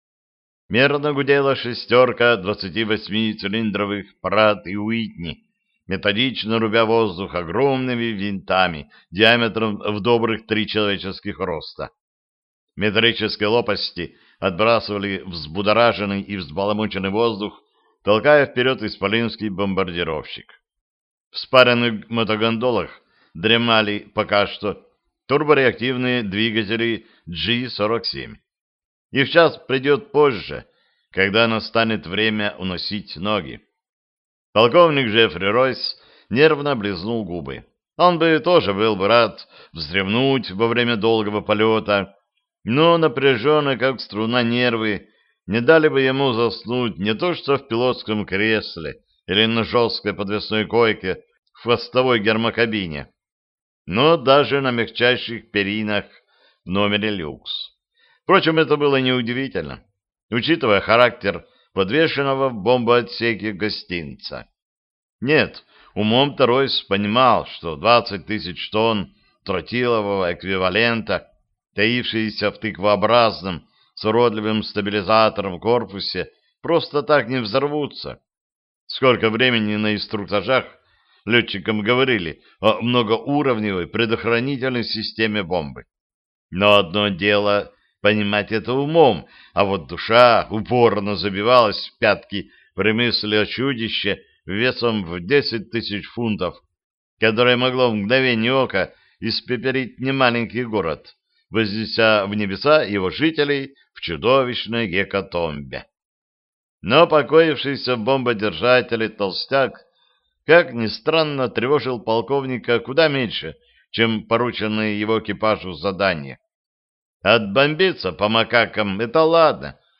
Аудиокнига Война или мир | Библиотека аудиокниг